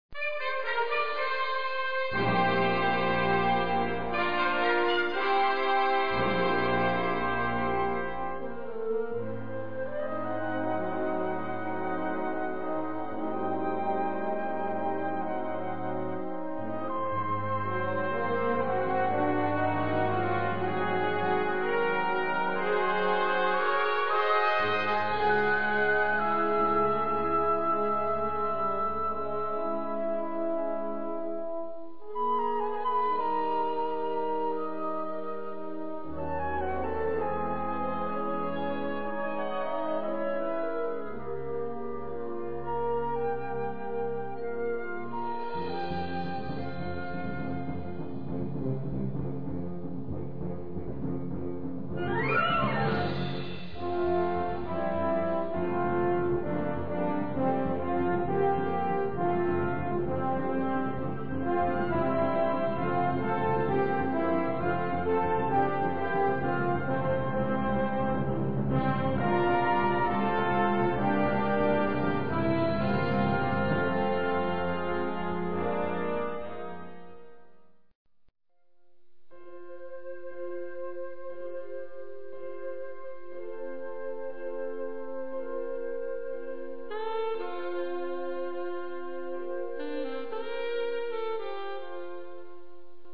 Blasorchester